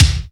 Kicks
WU_BD_273.wav